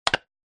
VmButtonPress.mp3